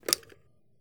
button_down.wav